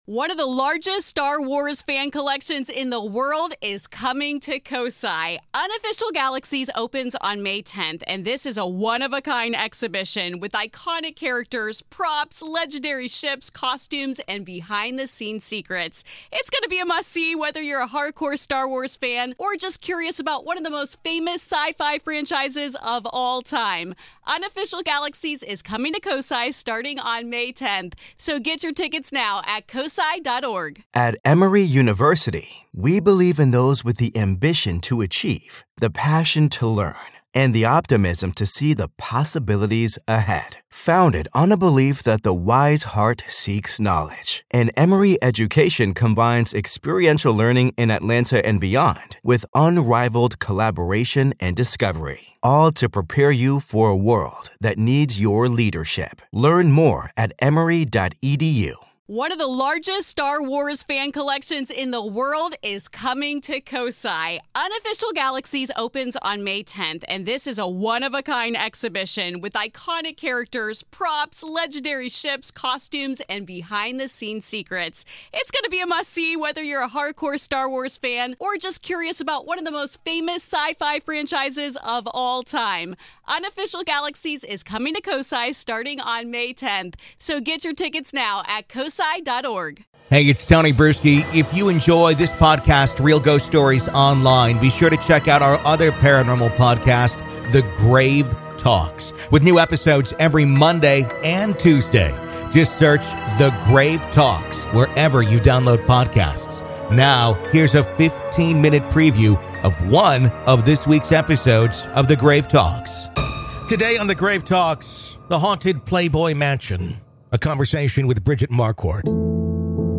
Haunted Playboy Mansion | A Conversation With Bridget Marquardt